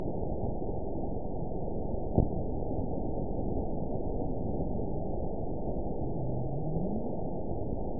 event 920314 date 03/15/24 time 09:05:05 GMT (1 year, 1 month ago) score 9.45 location TSS-AB01 detected by nrw target species NRW annotations +NRW Spectrogram: Frequency (kHz) vs. Time (s) audio not available .wav